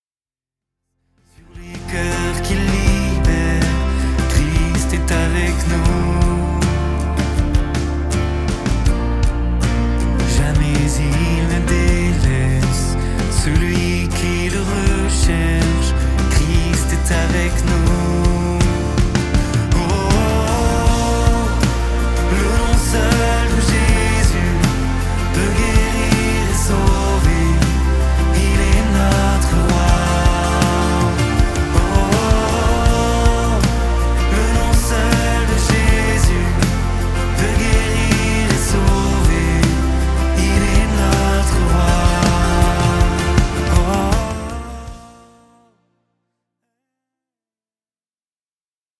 louanges